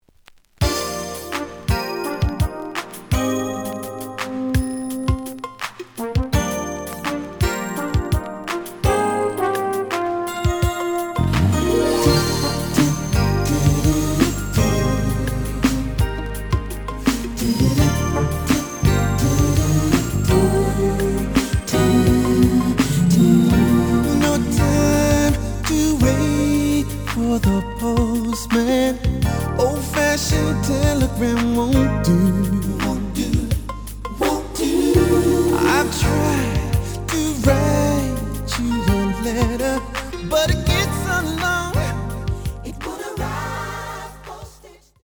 試聴は実際のレコードから録音しています。
●Genre: Soul, 80's / 90's Soul